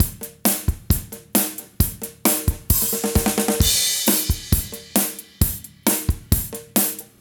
10 rhdrm133roll.wav